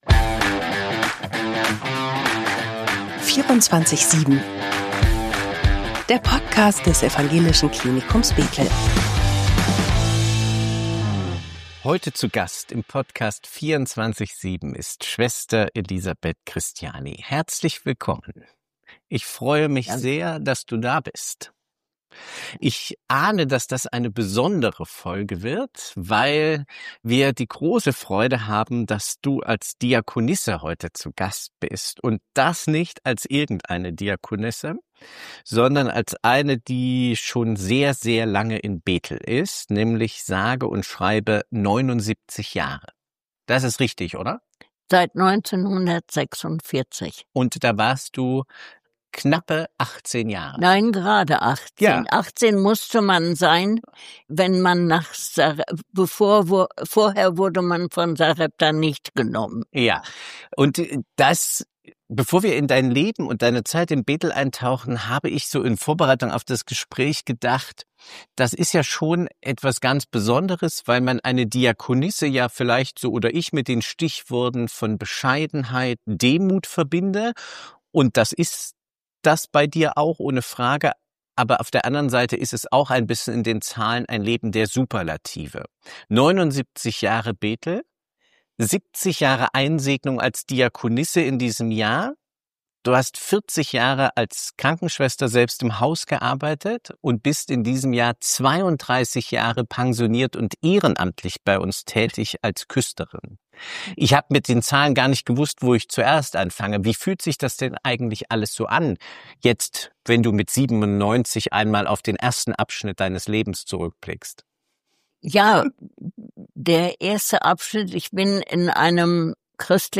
Eine Folge voller Geschichte, Humor und tiefer Dankbarkeit.